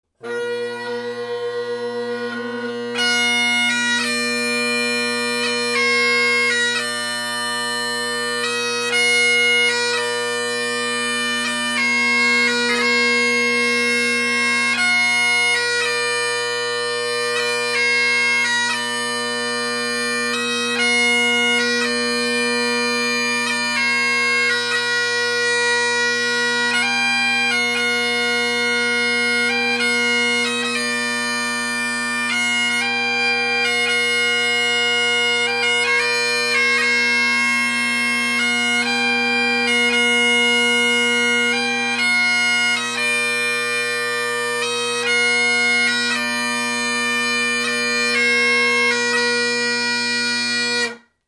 Fhuair Mi Pòg < a quick run through on the pipes